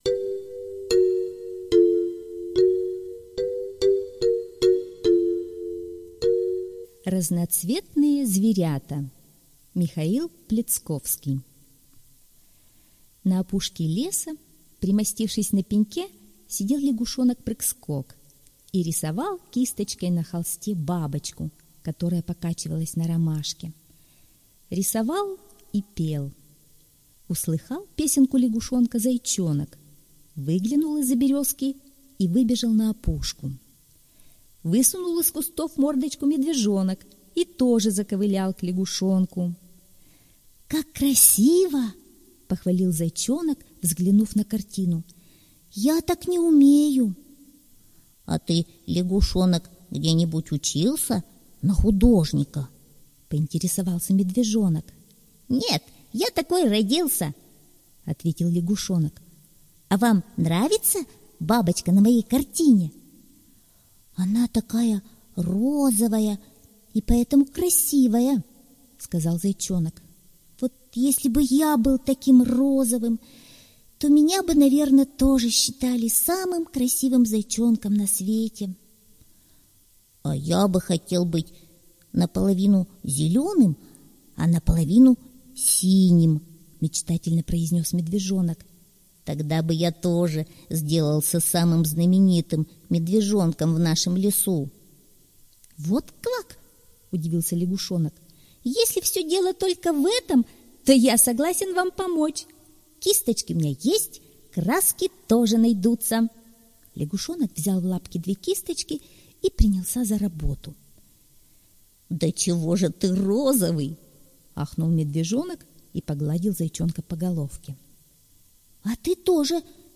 Разноцветные зверята - аудиосказка Пляцковского М.С. Разноцветные зверята — сказка о том, как лягушонок разукрасил зайчика и мишку красками.